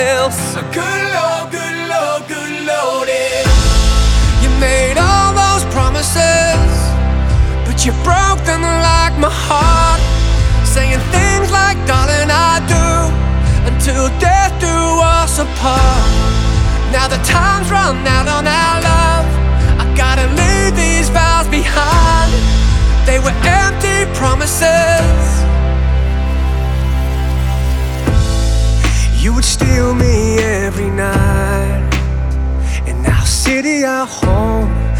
Жанр: Рок / Альтернатива